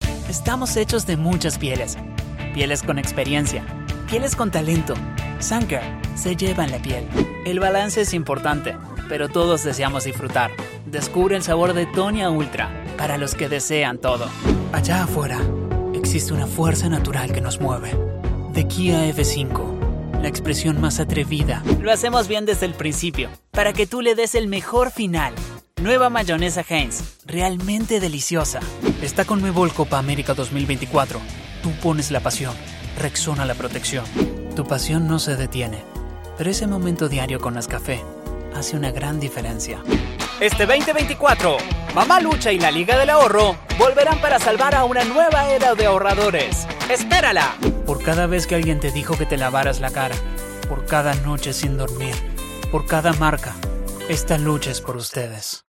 Espagnol (argentin)
Démo commerciale
BarytonBasseBas
De la conversationContentAmicalChaleureuxPrécis